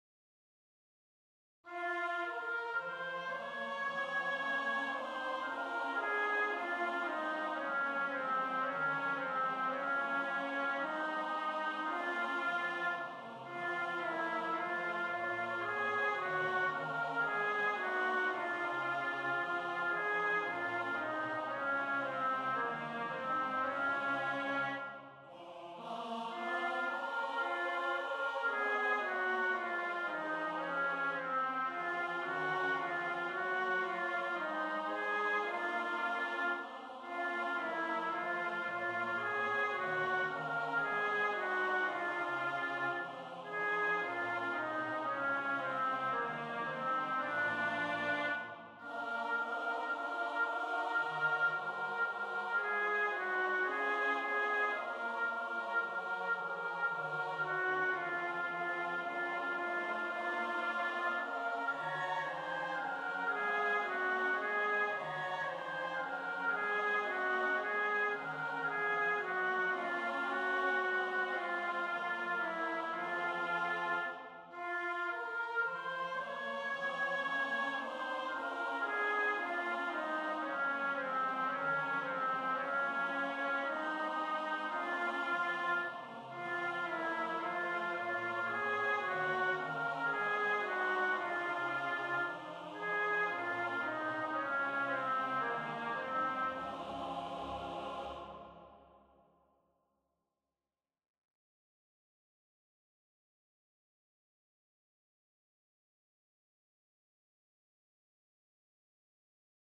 GAUDETE PRACTICE TRACKS:
4020-alto.mp3